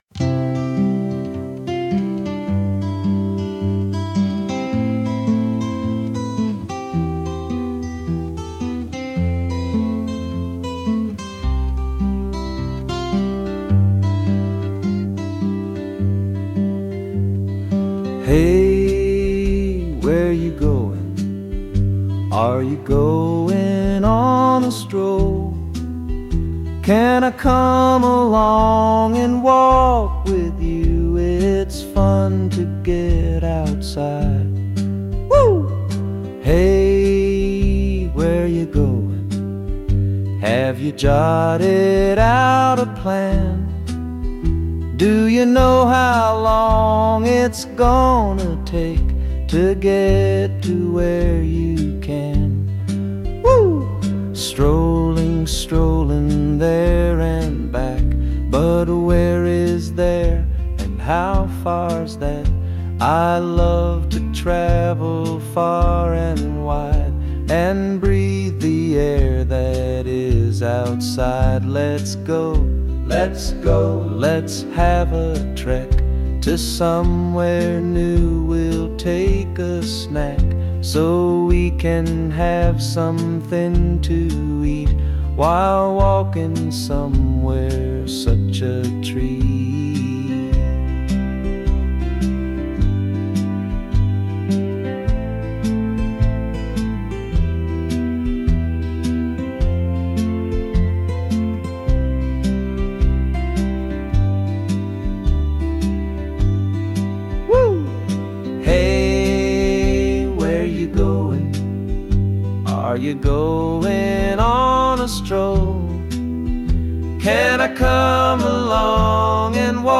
Sung by Suno
Strolling_To_Where_(Cover)_mp3.mp3